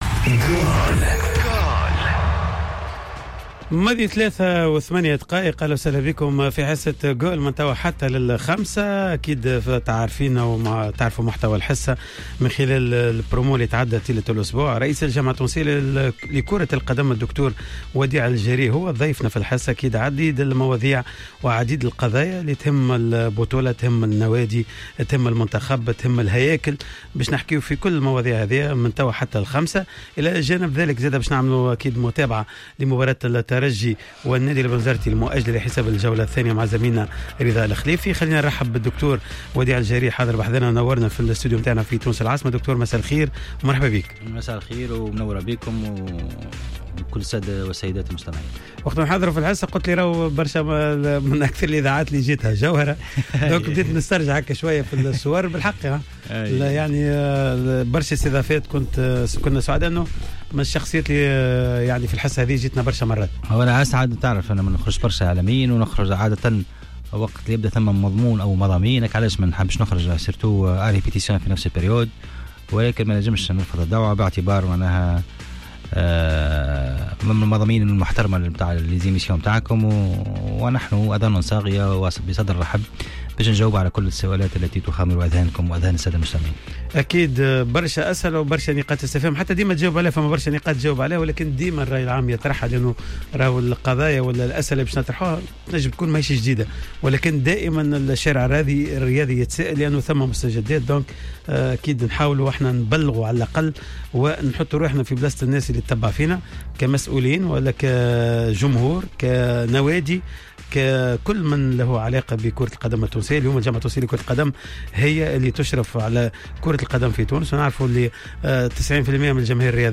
أشار رئيس الجامعة التونسية لكرة القدم وديع الجريء لدى حضوره في حصة "قوول" أن ترشحه لفترة نيابية قادمة في الجلسة الإنتخابية للجامعة التي ستنعقد في شهر مارس 2020 أمر وارد.